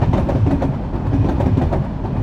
train3.ogg